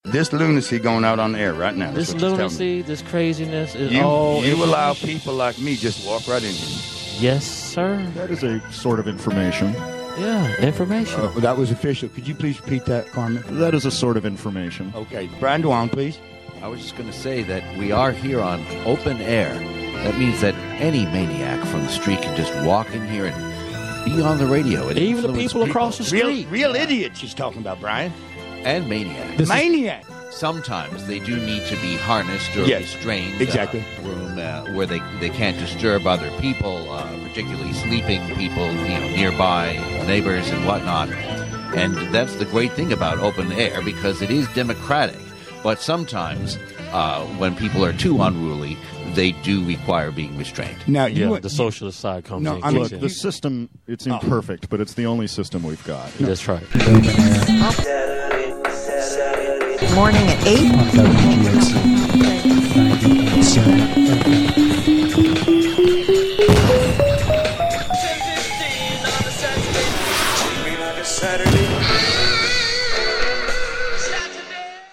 "Open Air" Promo, for show Saturdays 8-10 a.m. from Catskill Community Center.